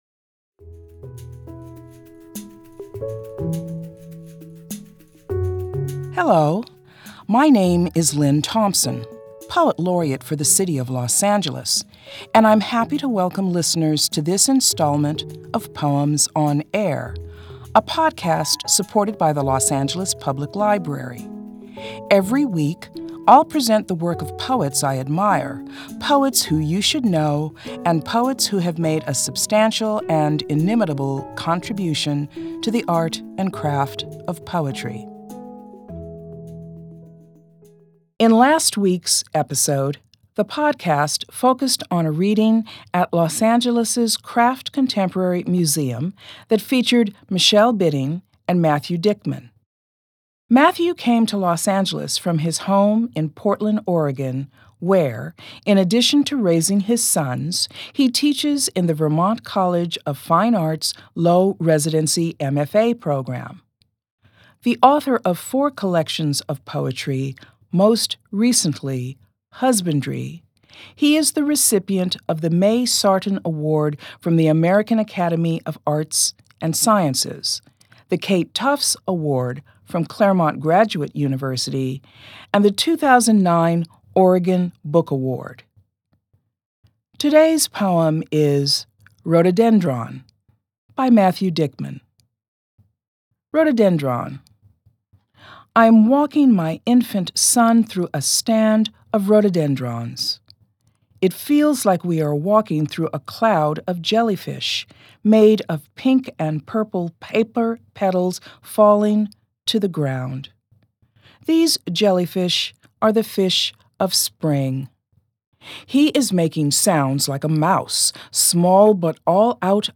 Los Angeles Poet Laureate Lynne Thompson reads Matthew Dickman's poem "Rhododendron."